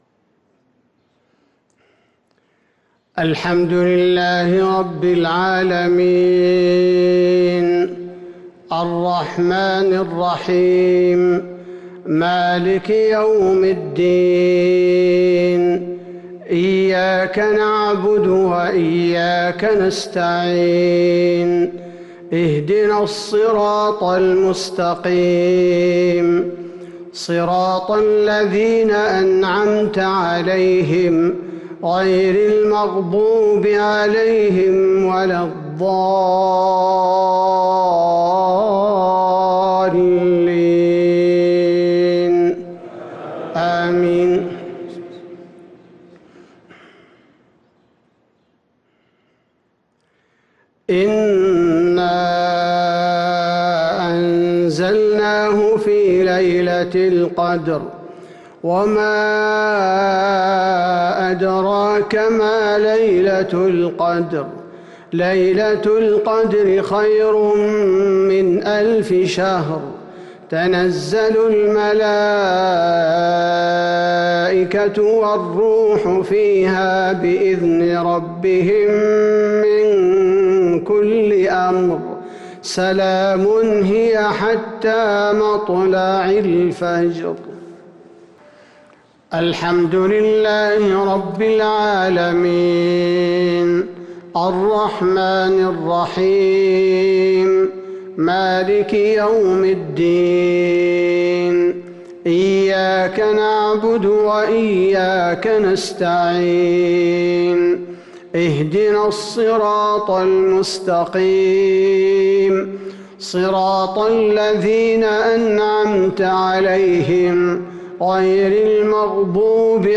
صلاة المغرب للقارئ عبدالباري الثبيتي 13 جمادي الأول 1443 هـ
تِلَاوَات الْحَرَمَيْن .